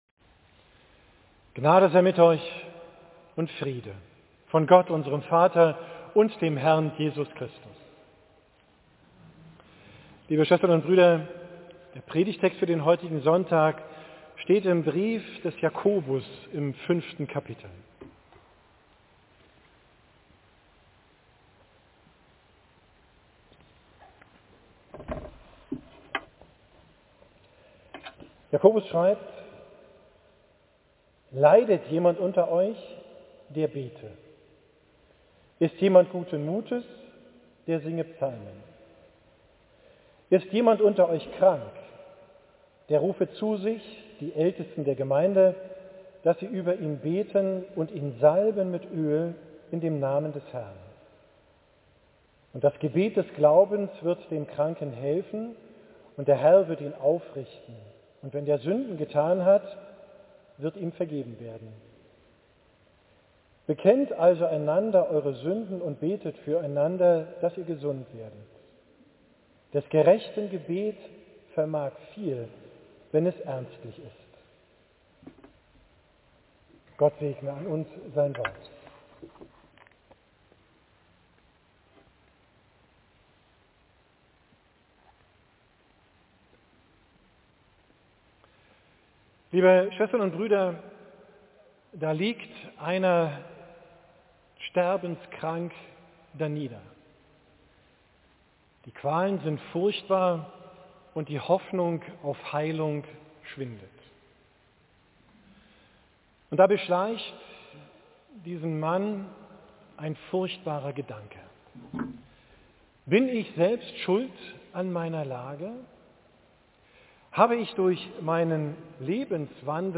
Predigt vom 19.